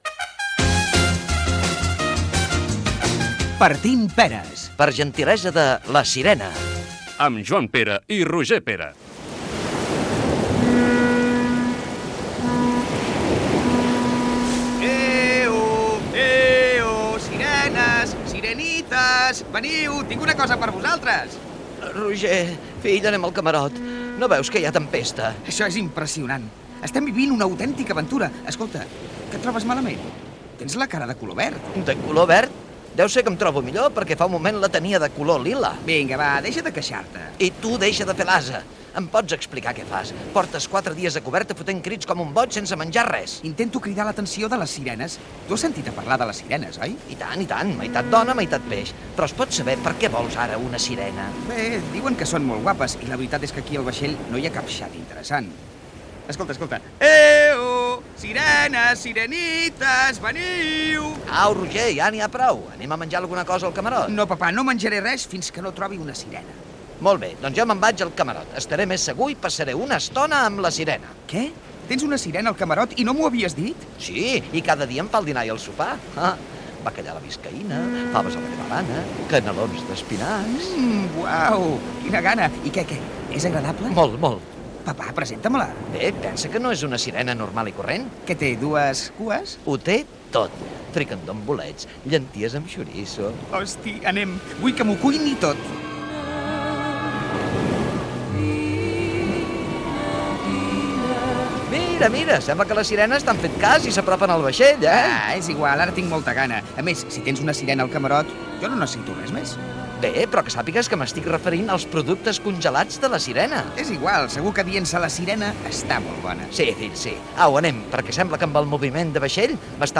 Indicatiu del programa, diàleg en un vaixell sobre les sirenes i els productes de la botiga de congelats